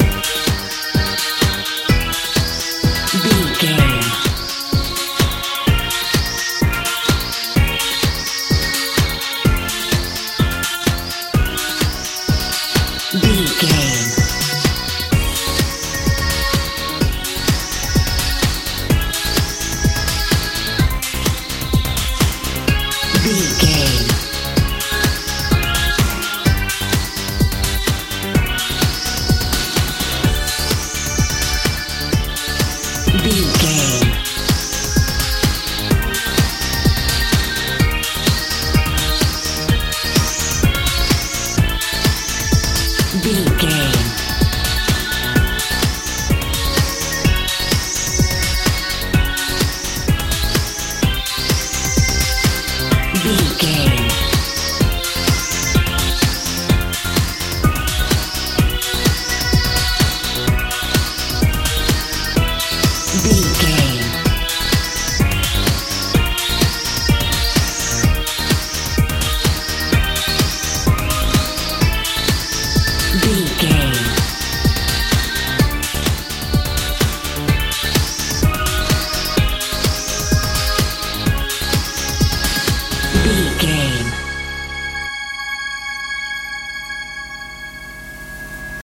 techno feel
Aeolian/Minor
mystical
strange
bass guitar
drums
synthesiser
80s
90s
suspense
tension